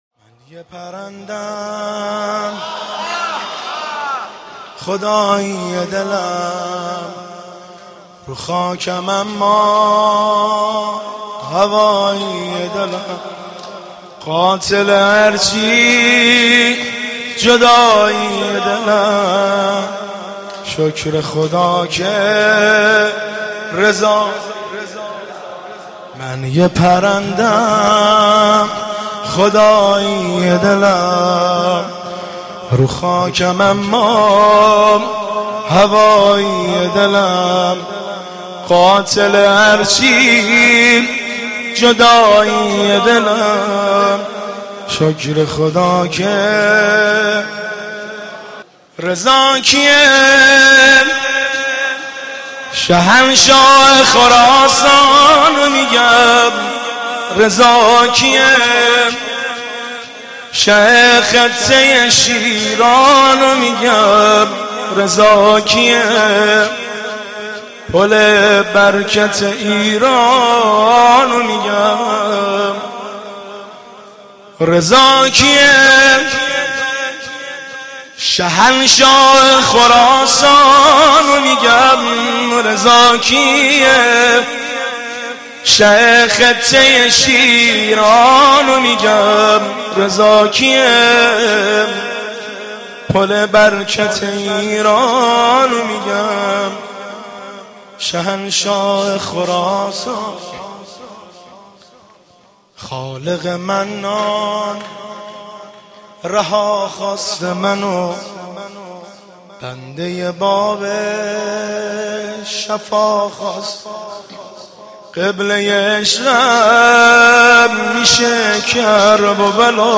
مجموعه نوحه های جلسه هفتگی 93/06/12
نوای گرم
در هیئت بین الحرمین تهران اجرا شده است
روز ولادت تو غزل آفریده شد ( مدح خوانی )